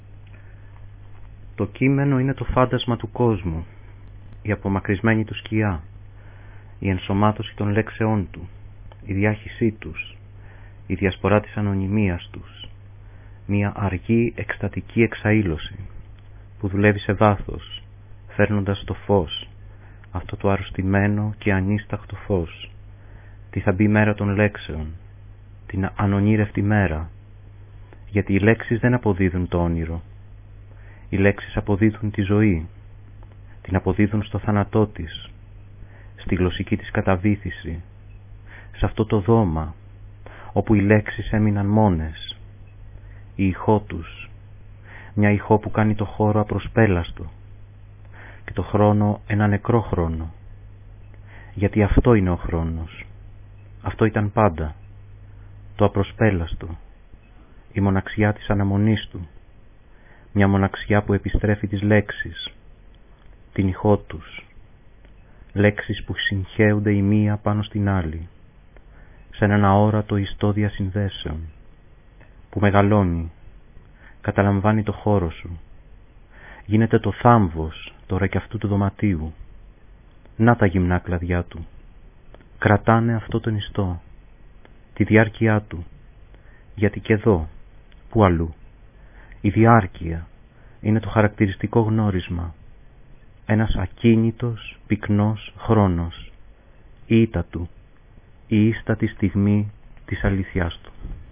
Το ηχητικό τοπίο της βραδιάς δημιουργήθηκε από: